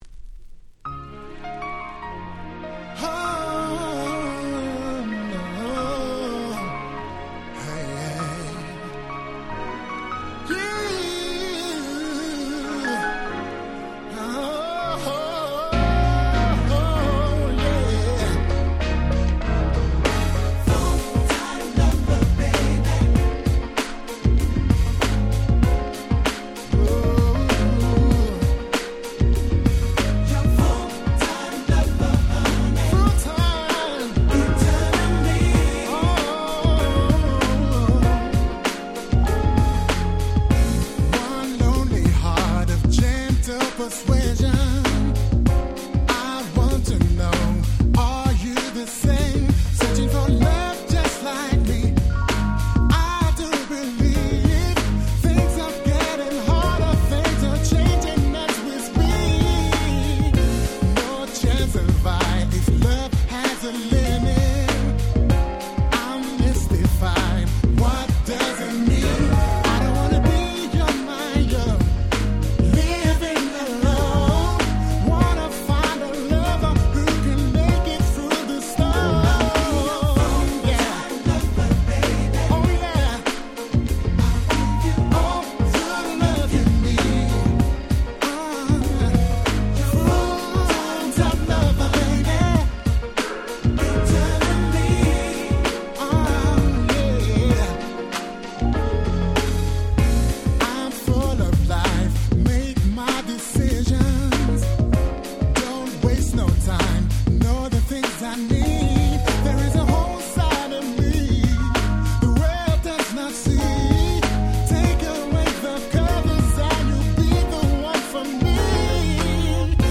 96’ Super Nice UK Street Soul / R&B Compilation !!